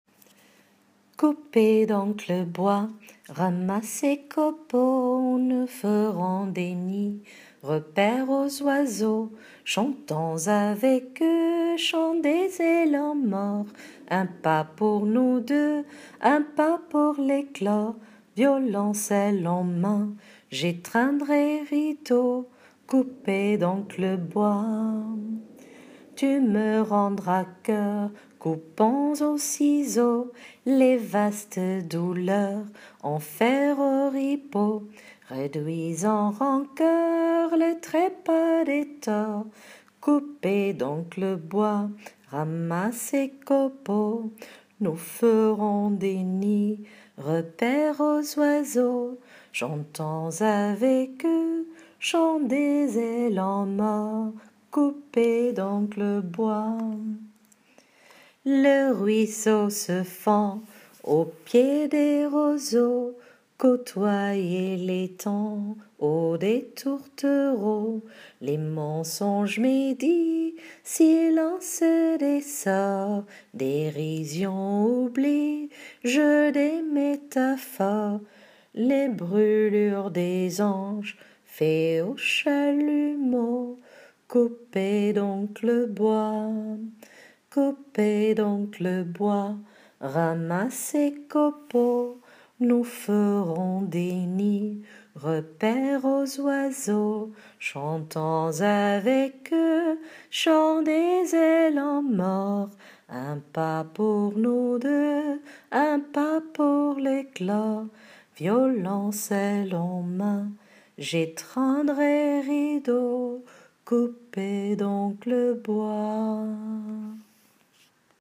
Essai de mettre en musique le poème